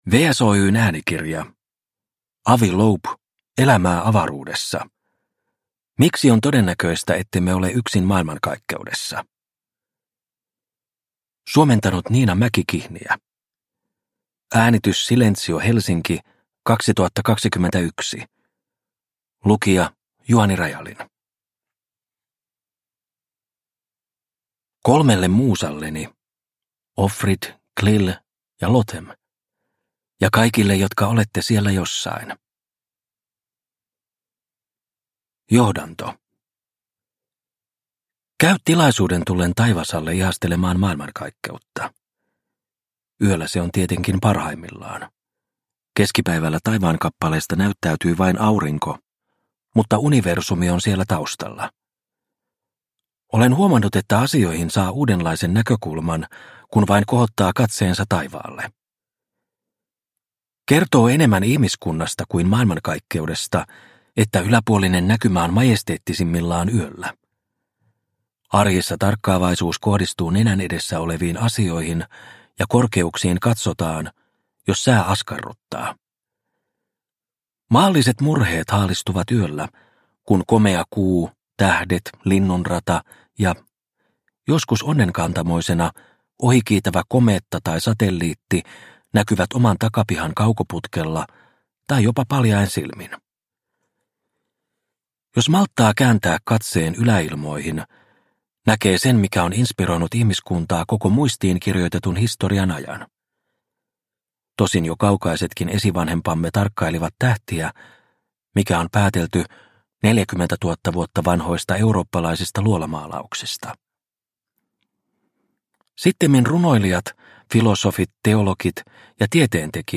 Elämää avaruudessa – Ljudbok – Laddas ner